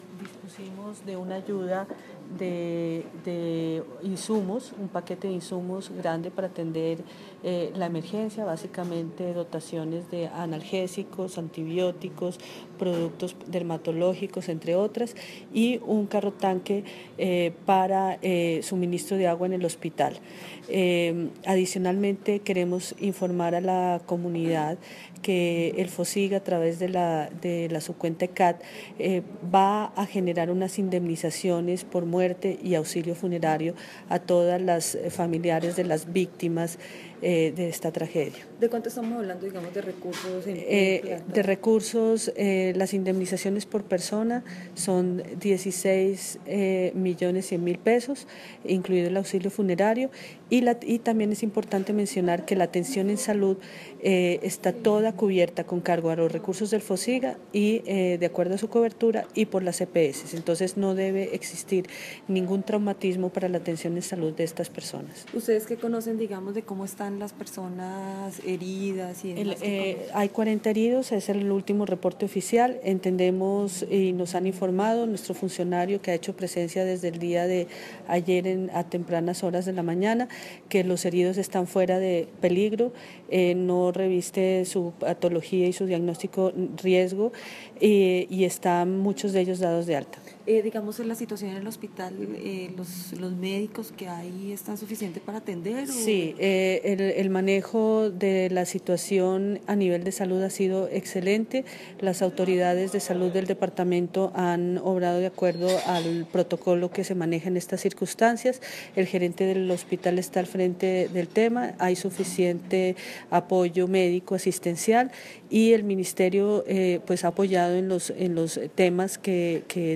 Audio: La Ministra (e) de Salud y Protección Social, Carmen Eugenia Dávila Guerrero habla sobre la atención a victimas en Salgar (Antioquia)